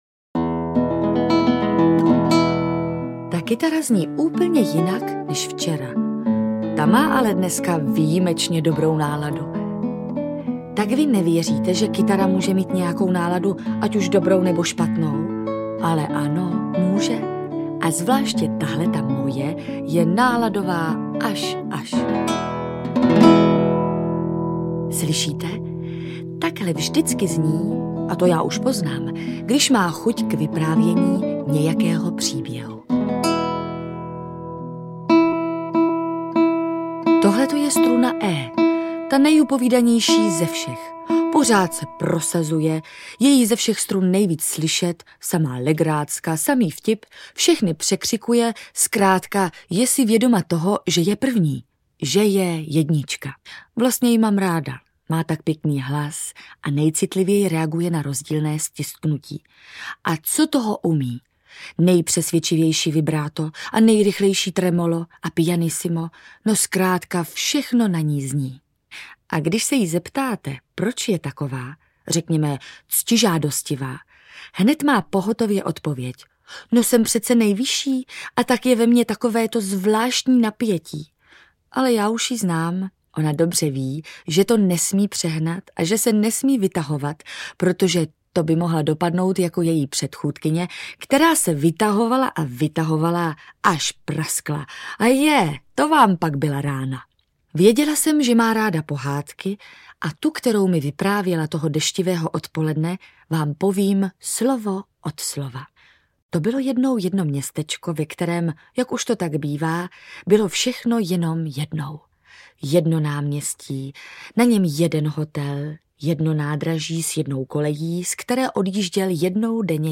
Pohádky Zdeňka Rytíře vypravuje na této audioknize Lenka Filipová.
AudioKniha ke stažení, 10 x mp3, délka 54 min., velikost 48,7 MB, česky